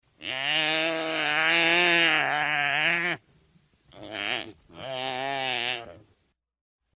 WILDEBEEST
Wildbeest.mp3